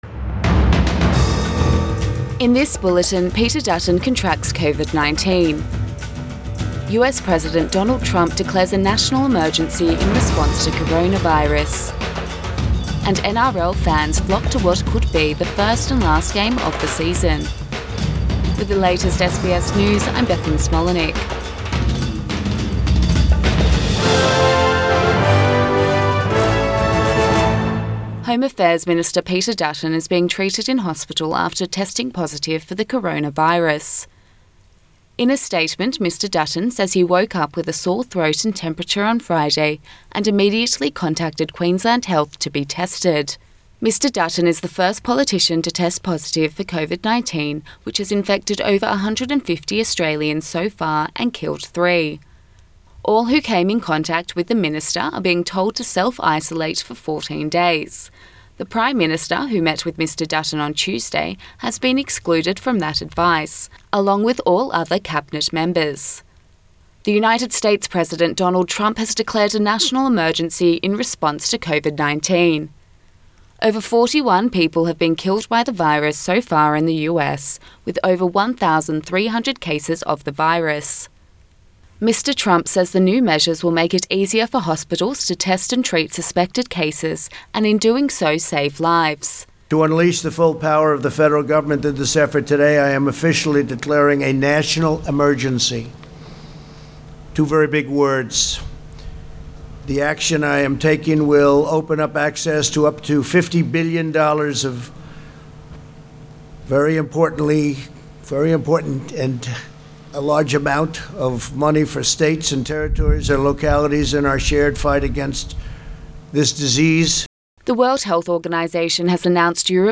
AM bulletin 14 March 2020